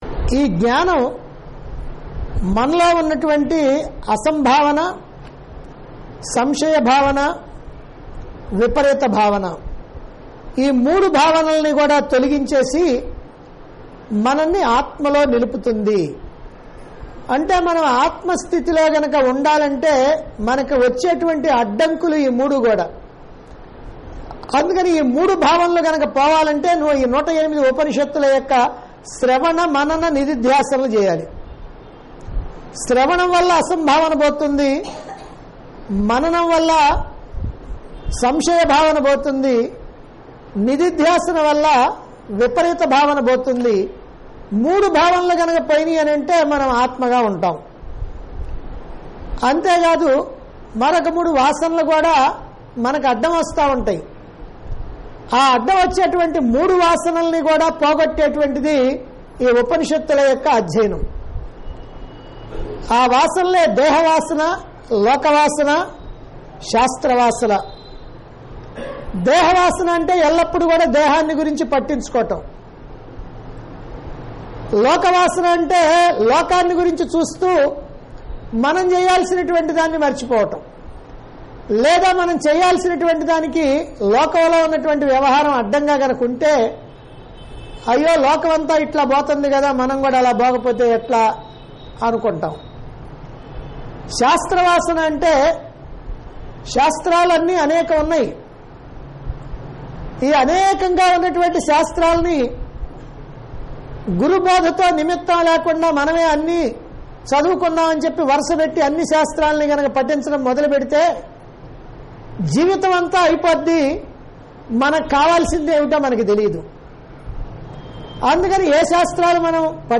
Aadhyatmikam - Questions & Answers
Discourse Conducted At Chilakaluripet, Guntur Dt. Andhra Pradesh.